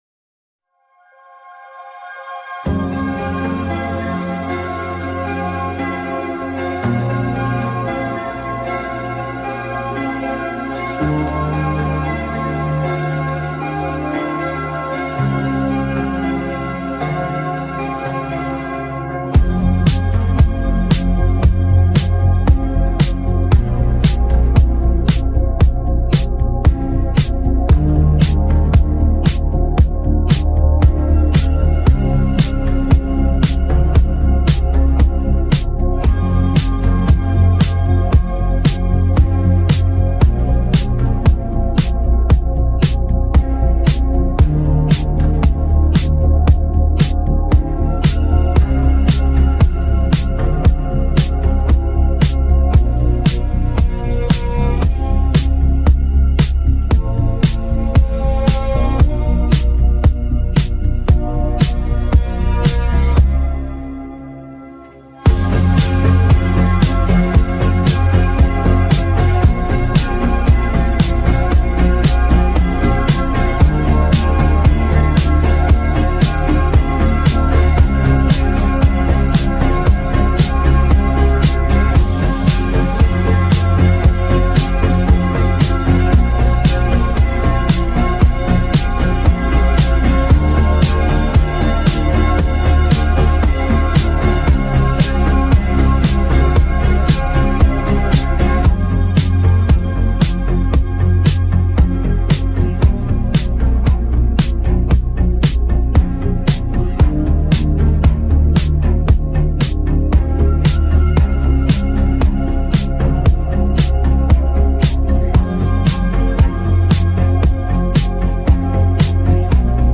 This instrumental is so twinkle sparkle, so beautiful